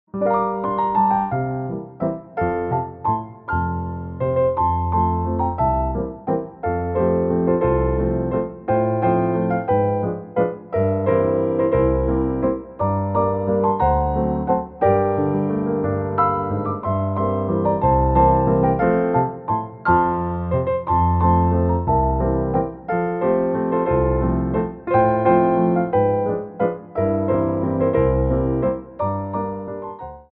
33 Tracks for Ballet Class.
Tendus
3/4 (16x8)